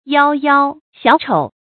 幺幺小丑 yāo mó xiǎo chǒu
幺幺小丑发音